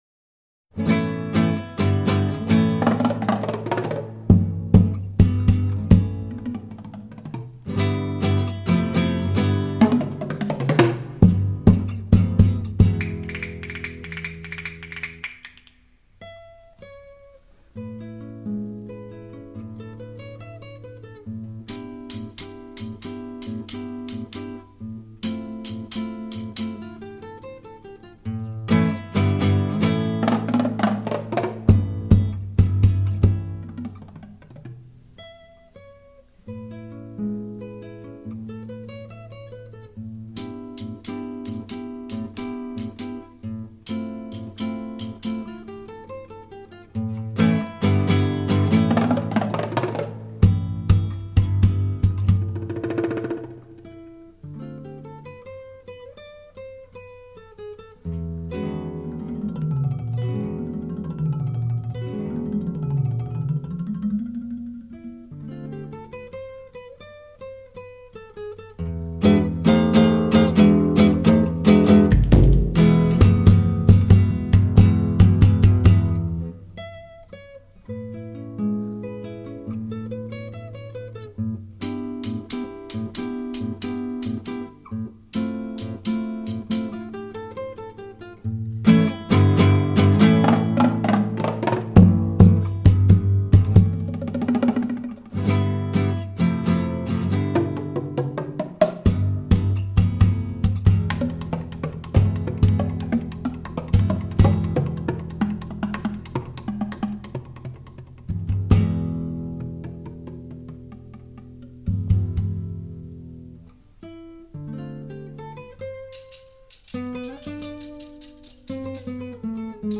★   爵士鼓與吉他二重奏的超級音響效果饗宴！